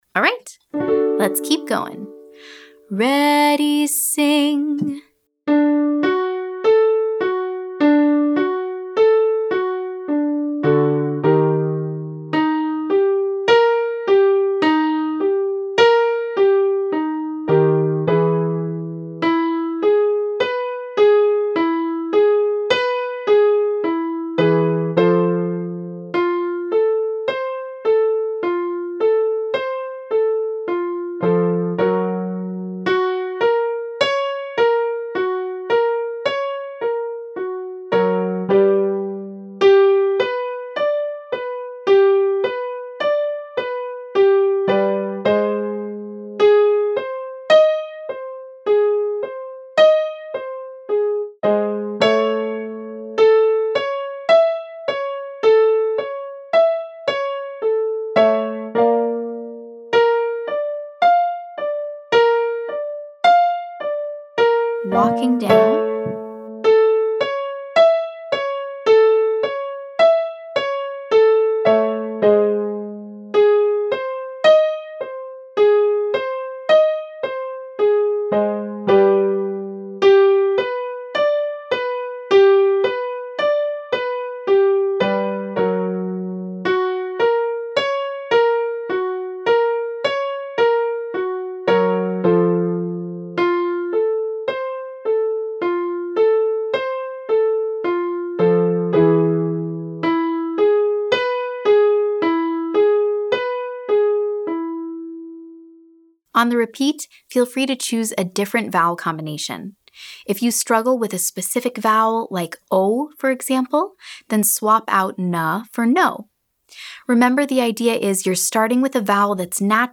Bright & “Bratty” Sounds - Online Singing Lesson
• Start by singing “buh-buh-buh” on a five-note scale: C – D – E – F – G
• Focus on singing with a bright, sharp tone.